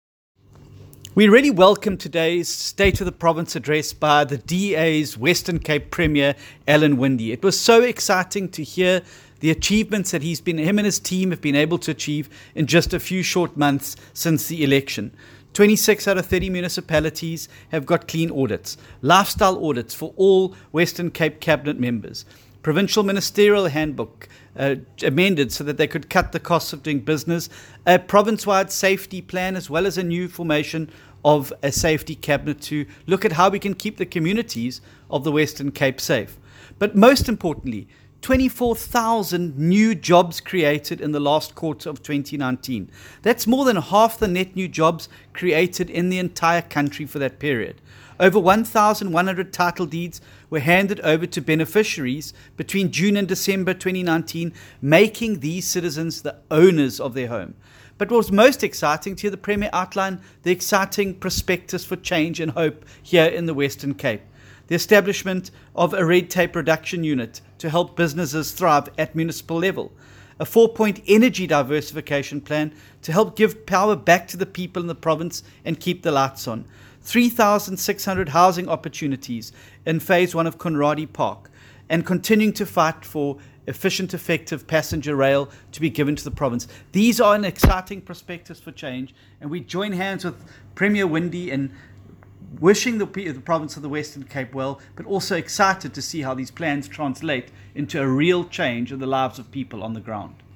soundbite by John Steenhuisen MP.